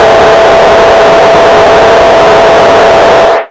RADIOFX  5-L.wav